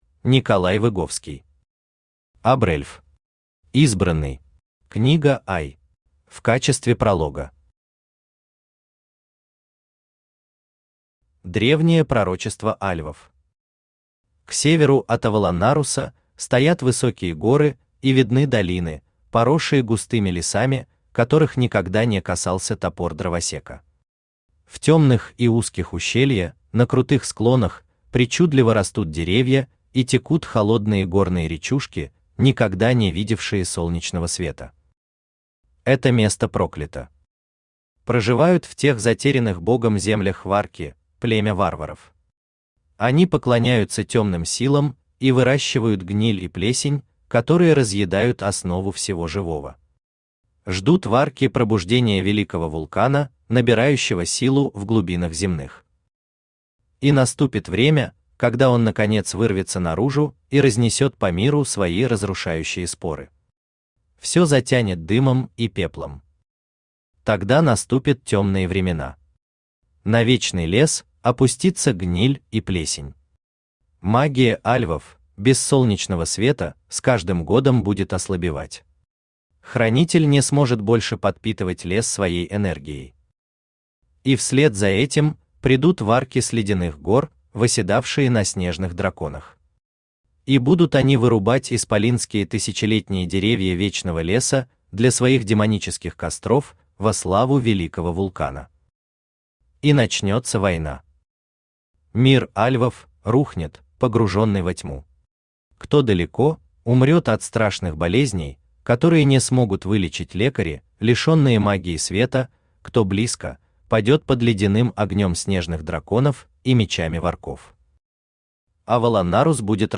Аудиокнига Обрэльф. Избранный. Книга I | Библиотека аудиокниг
Книга I Автор Николай Выговский Читает аудиокнигу Авточтец ЛитРес.